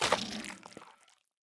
Minecraft Version Minecraft Version snapshot Latest Release | Latest Snapshot snapshot / assets / minecraft / sounds / block / sculk / break11.ogg Compare With Compare With Latest Release | Latest Snapshot
break11.ogg